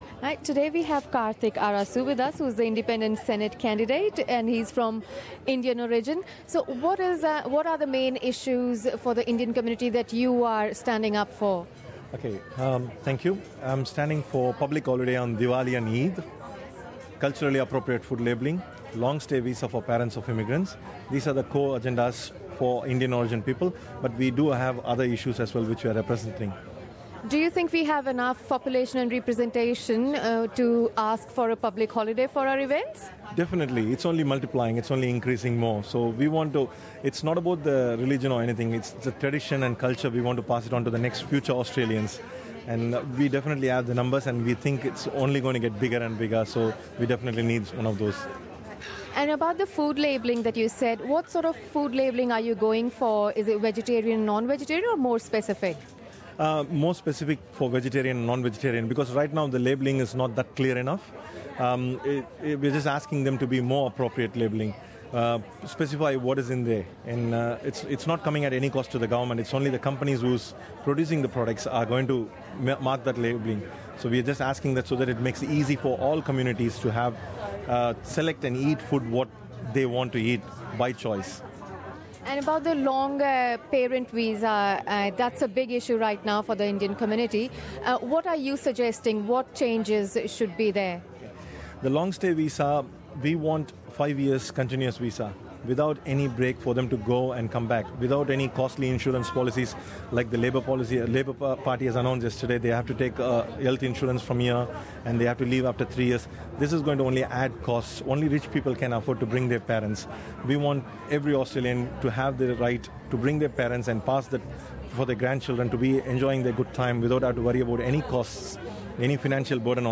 SBS Radio held an Election Exchange on Sunday the 19th of June 2016 on Federation Square, Melbourne where candidates and representatives from all parties and members of the community were given open invitation to participate in a healthy exchange of political views before the upcoming Federal Election on the 2nd of July 2016.